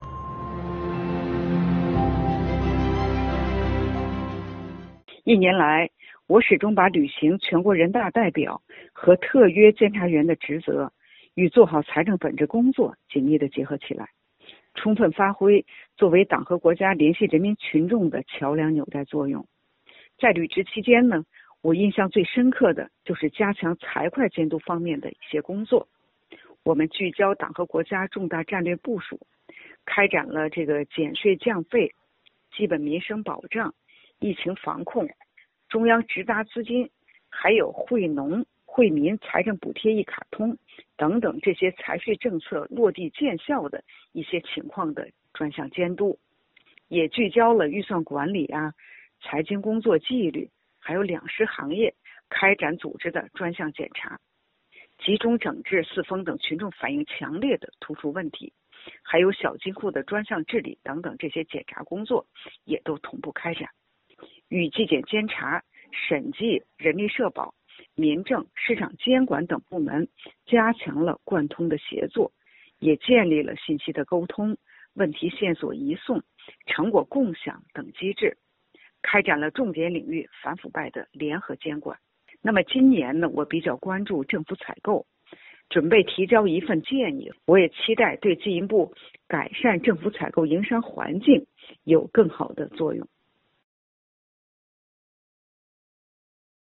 专访特约监察员丨把监督融入履职过程